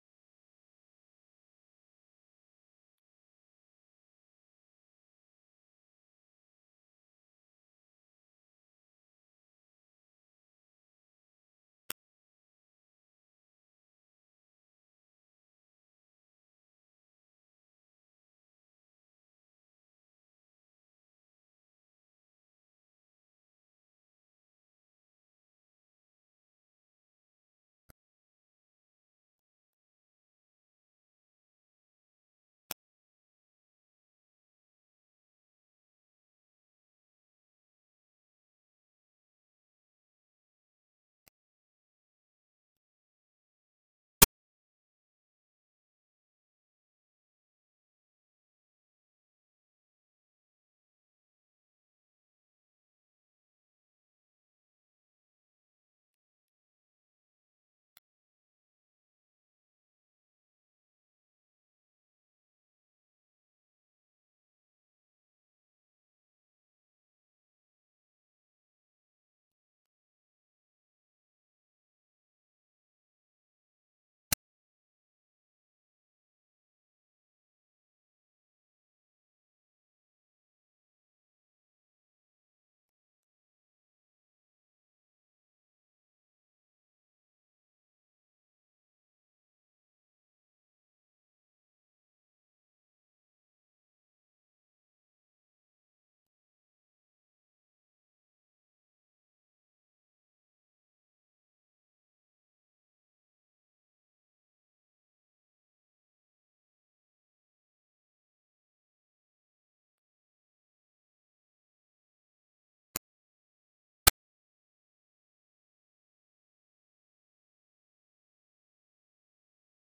country - guitare - bluesy - cosy - harmonica